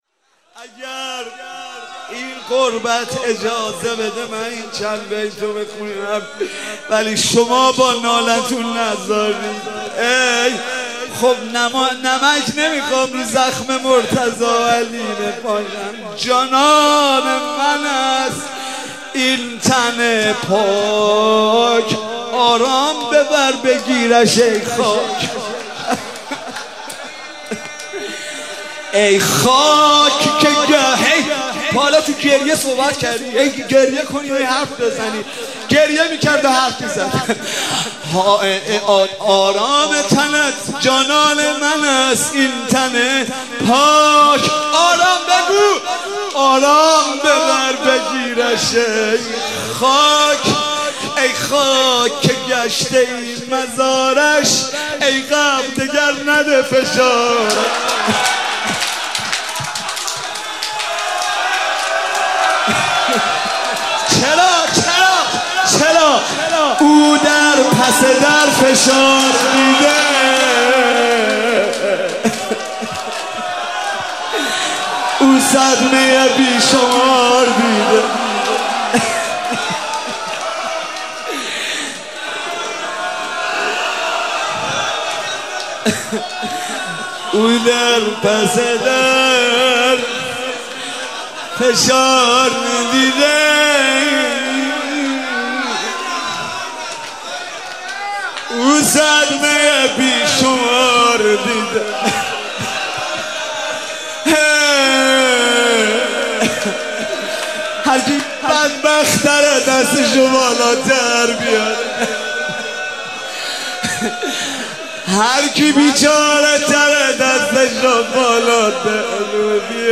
هفتگی 2 شهریور96 - روضه - جانان من است این تن پاک
مداحی